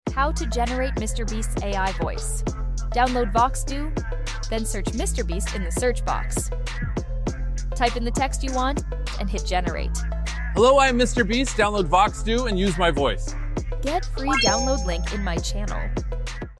How to generate Mr Beast’s AI voice | Free celebrity Text to speech tool